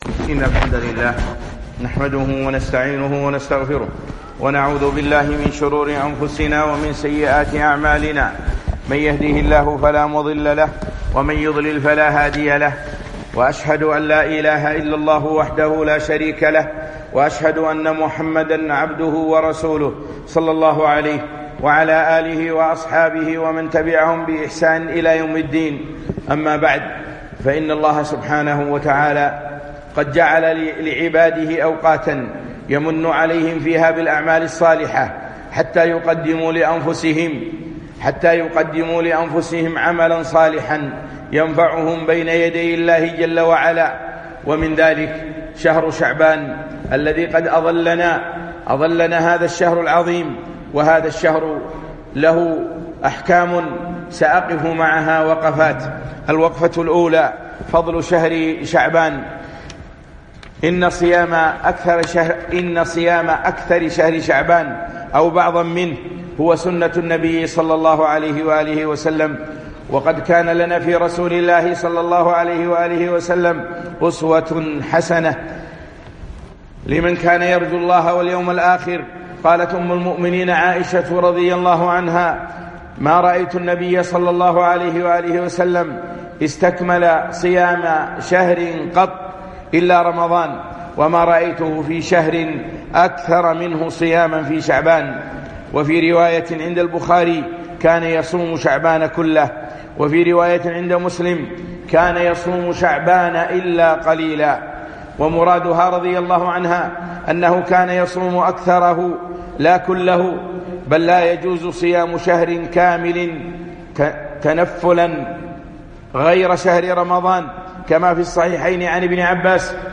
خطبة - وقفات مع شهر شعبان